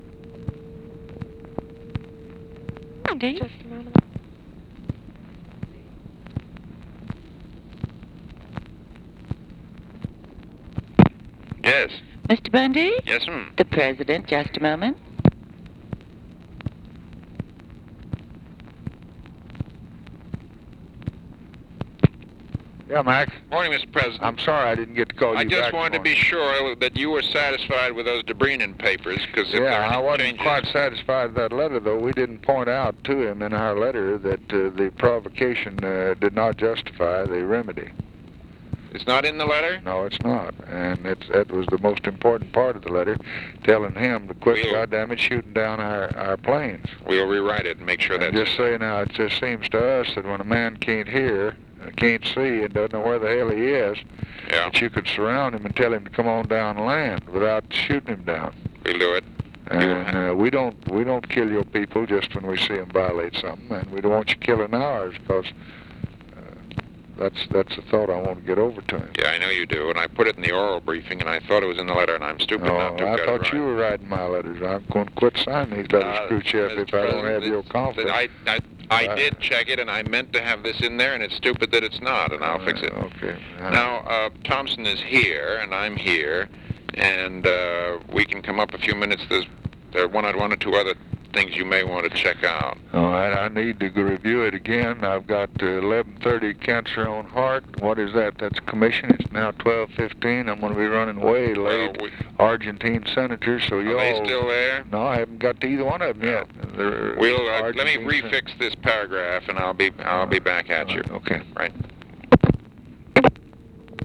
Conversation with MCGEORGE BUNDY, April 17, 1964
Secret White House Tapes